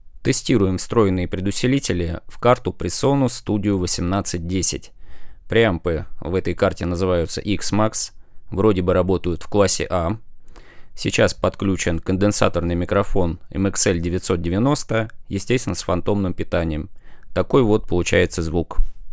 потестил предусилители в сравнении с преампом на THAT1510, результат в аттаче.
Вложения mxl990_XMAXpresonus.wav mxl990_XMAXpresonus.wav 1,6 MB · Просмотры: 592 mxl990_that1510.wav mxl990_that1510.wav 1 MB · Просмотры: 562